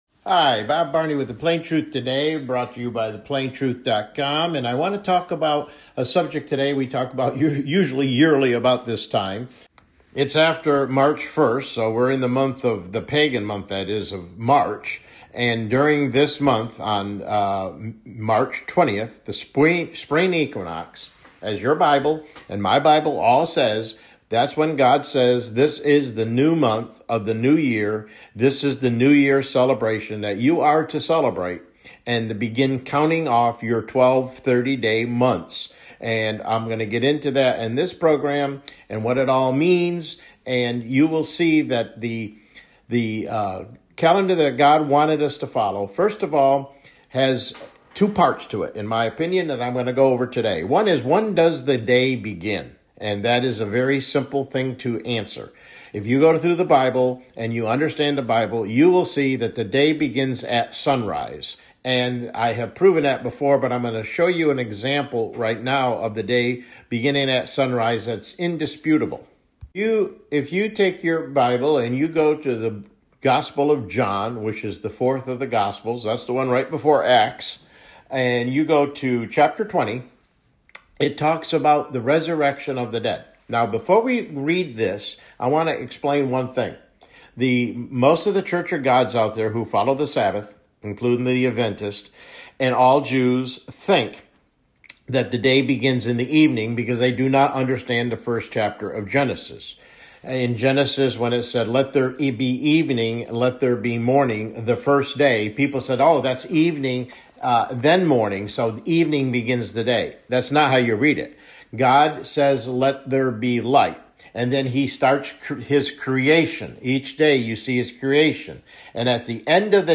CLICK HERE TO LISTEN TO THE PLAIN TRUTH TODAY MIDDAY BROADCAST: God’s Calendar and When the New Year Begins